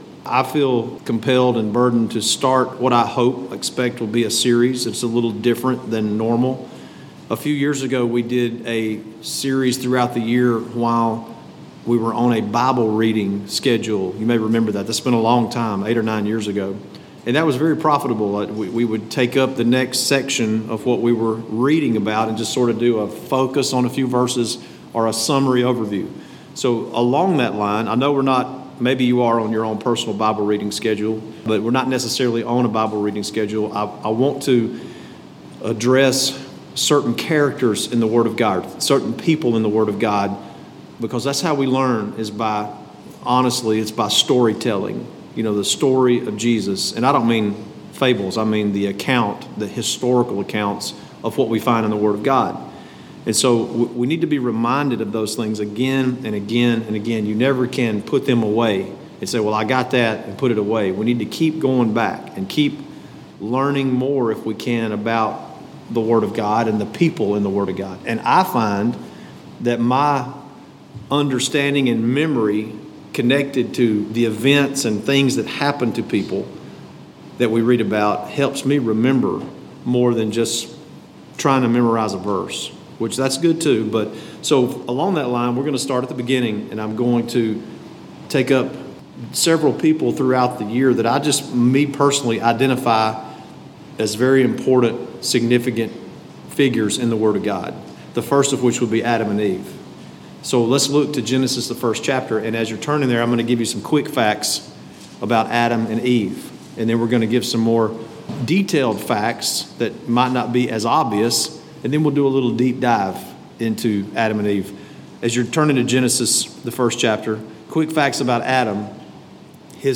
On our Wednesday night service, I have begun a series that looks at certain Biblical characters. The first two characters are Adam and Eve.